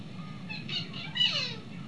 "PRETTY CLAUDE" is fairly good.
PCLAUDE.WAV